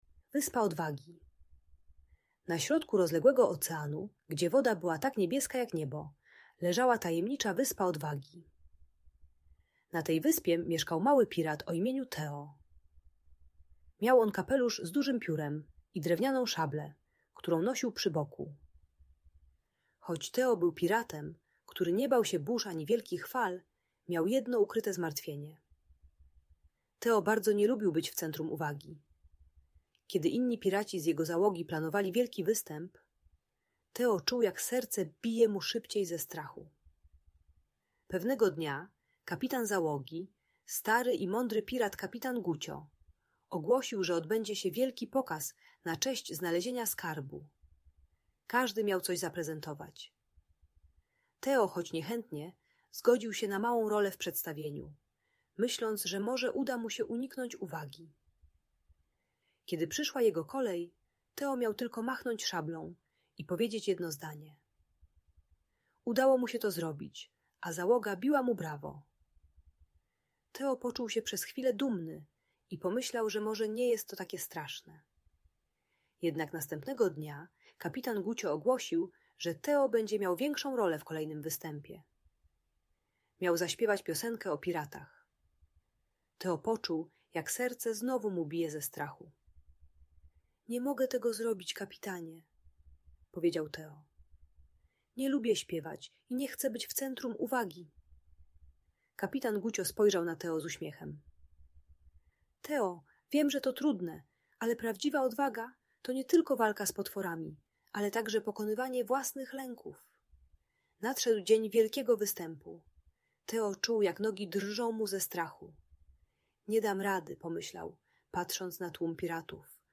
Wyspa Odwagi - Lęk wycofanie | Audiobajka